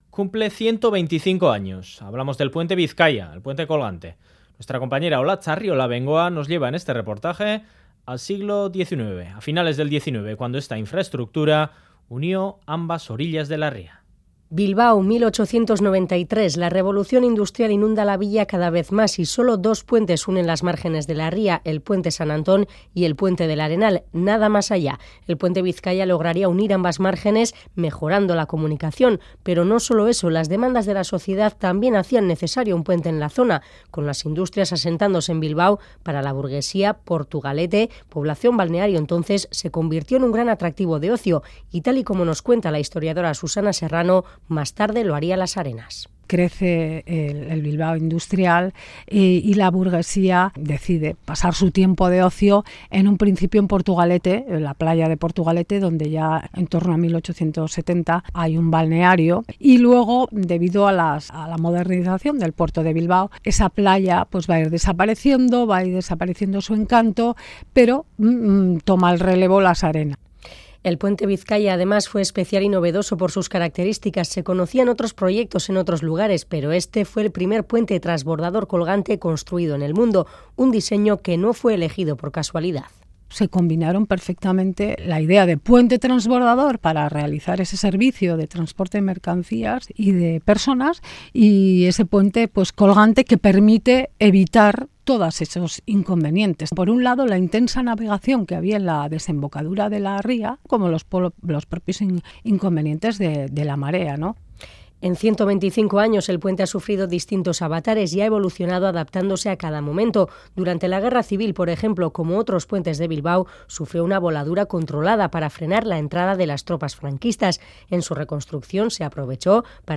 Radio Euskadi REPORTAJES